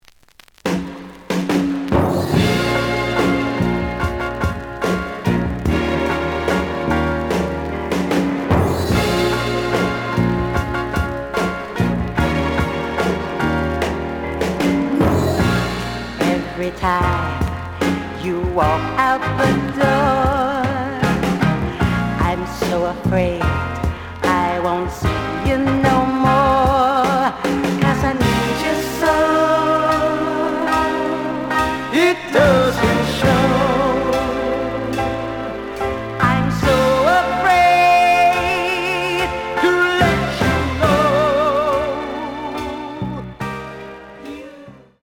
The audio sample is recorded from the actual item.
●Genre: Soul, 70's Soul
Edge warp.